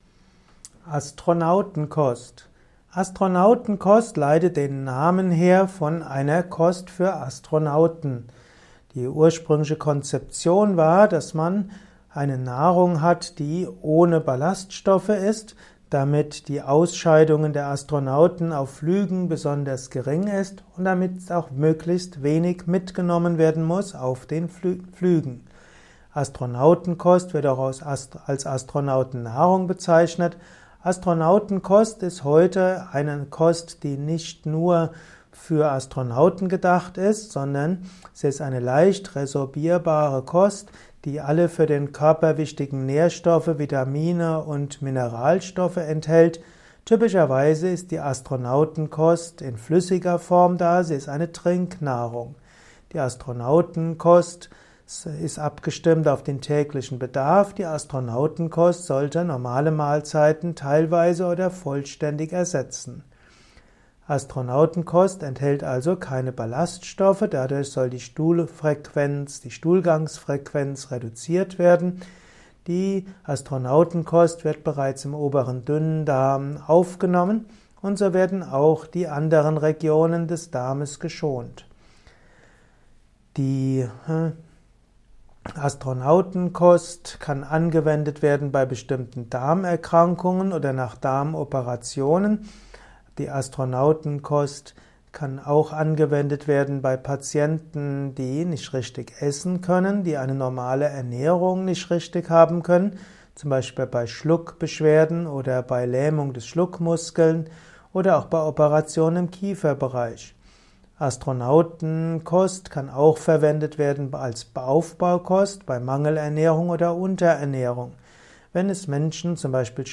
Kompakte Informationen zur Astronautenkost in diesem Kurzvortrag